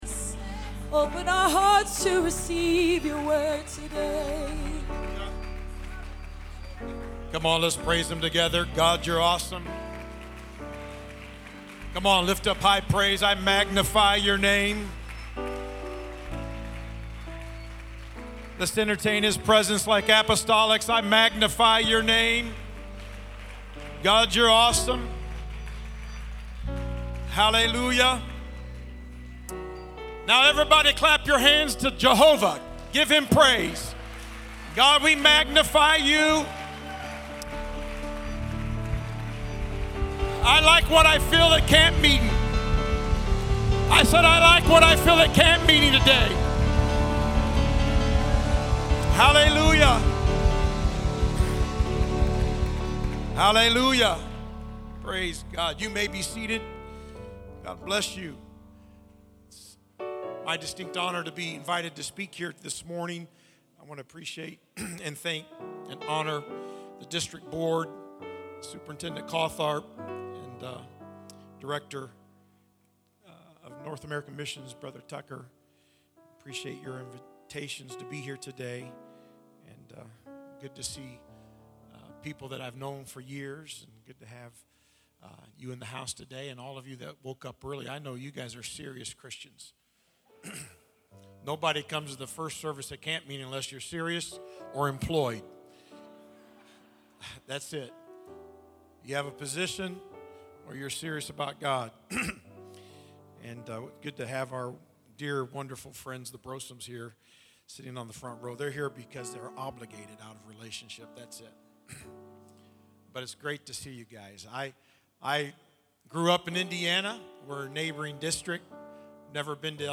Sermon Archive | Illinois District